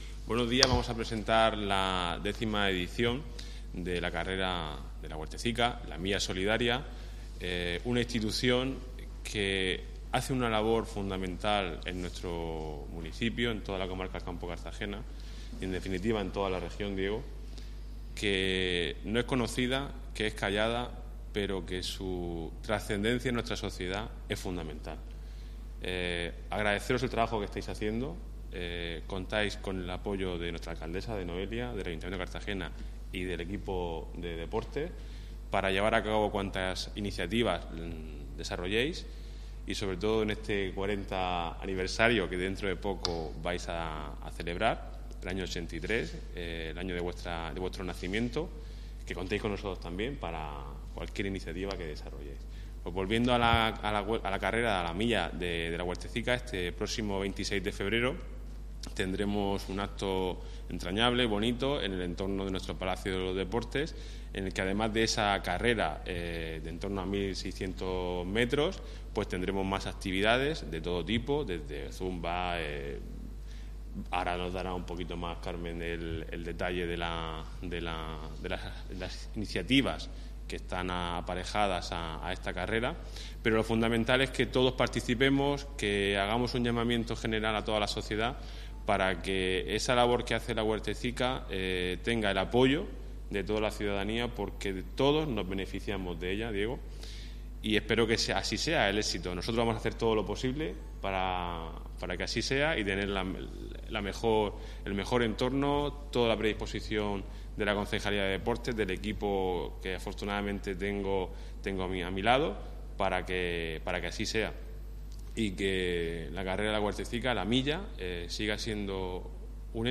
Enlace a Presentación de la Milla solidaria de La Huertecica